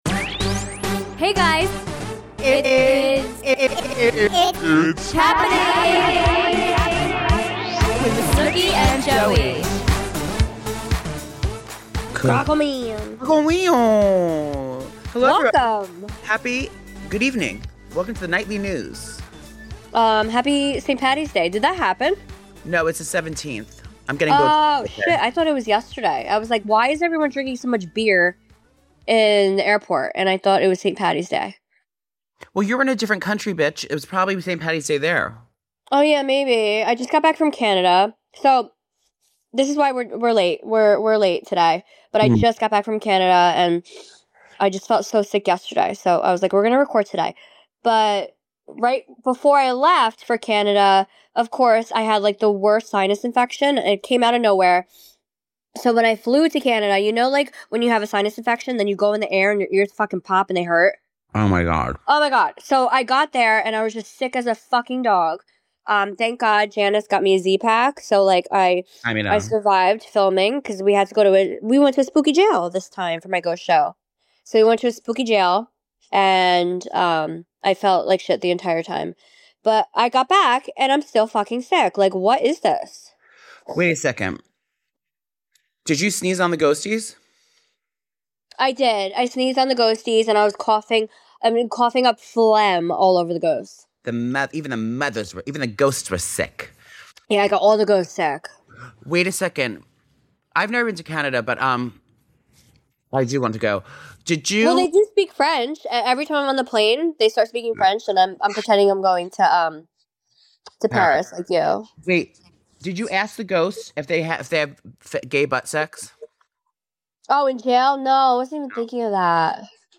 Note: this is a shorter episode due to technical difficulties.